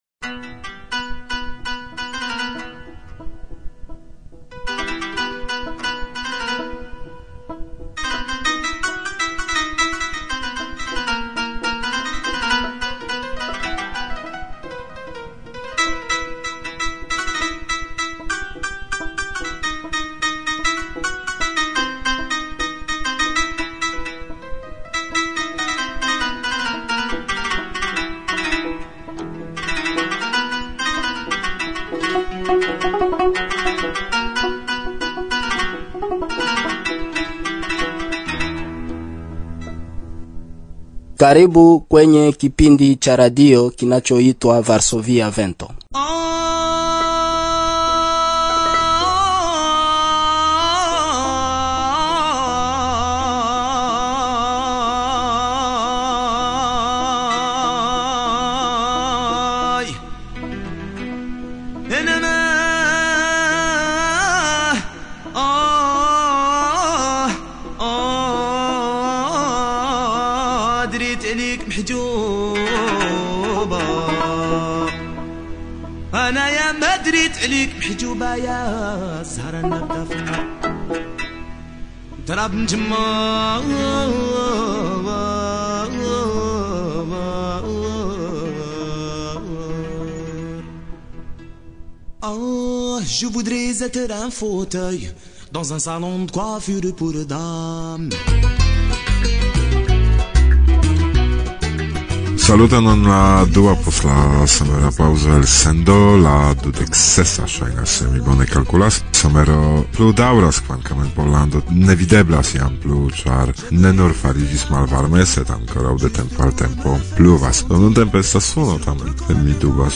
El la monda muziko
Libera momento kun brazila muziko